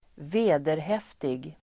Uttal: [²v'e:derhef:tig]